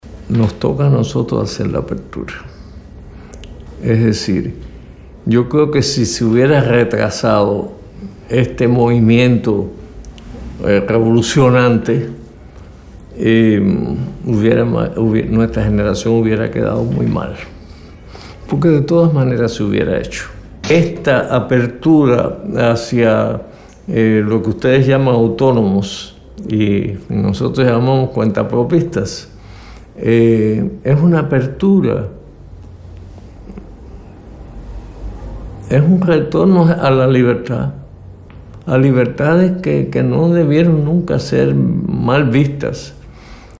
“Yo asumo todos los errores de la revolución, me siento responsable de ellos también", declaró Alfredo Guevara a la televisión española.